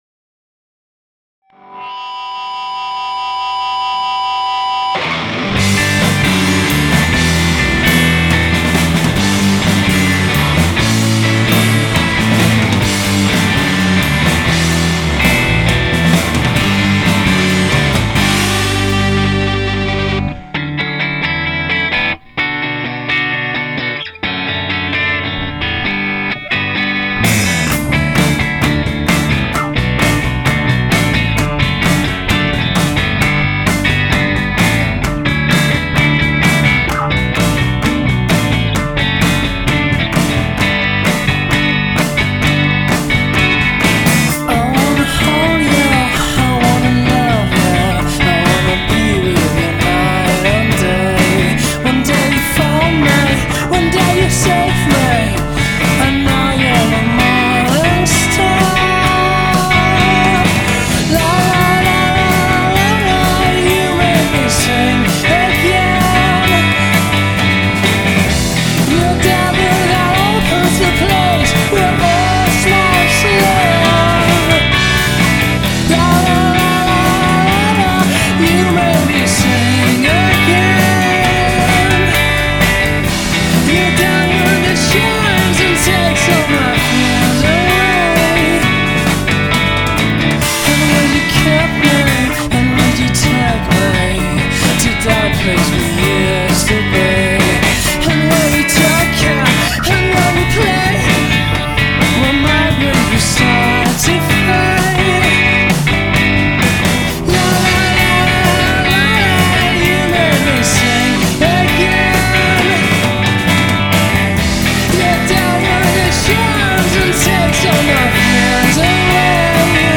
Guitar,vocal
Guitar,ambient
Bass
Drum